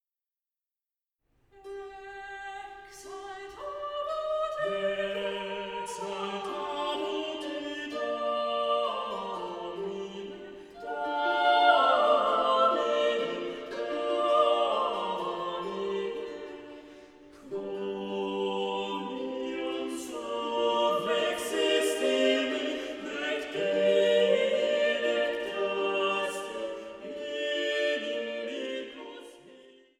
Leitung und Orgel